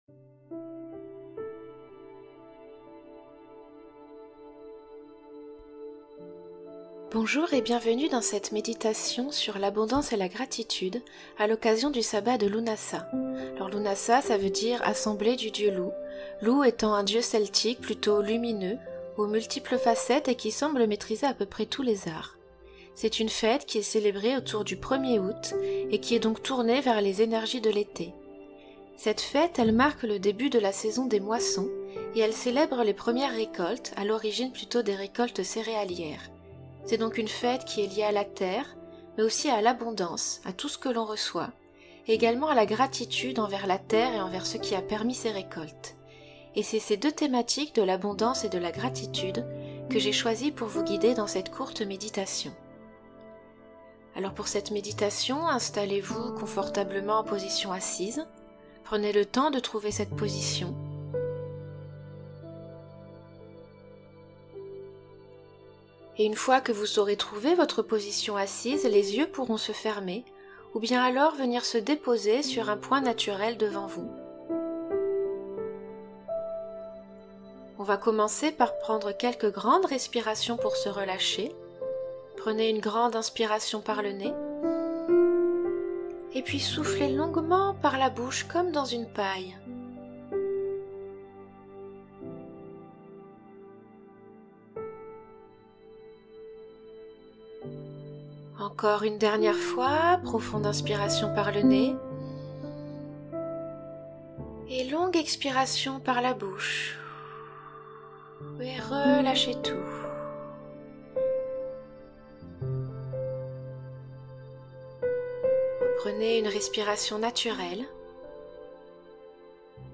Exercices de sophrologie
audio-meditation-Lughnasadh.mp3